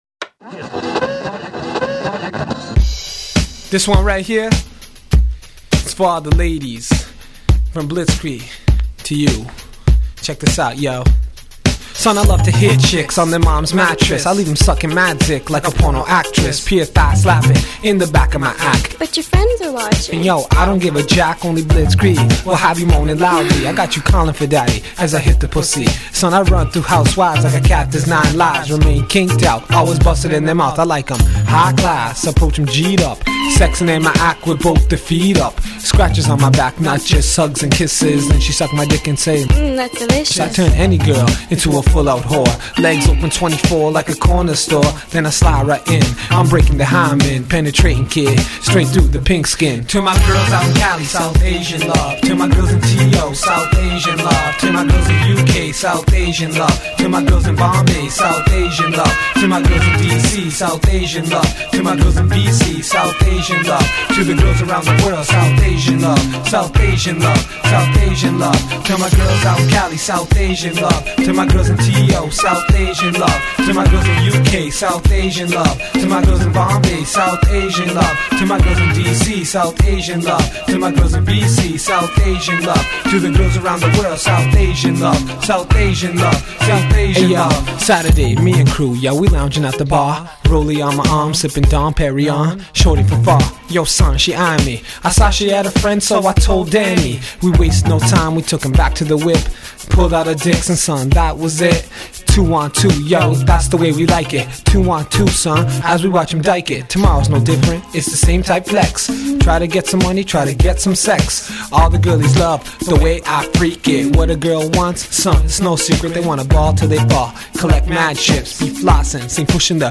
Canadian Urban Music Compilation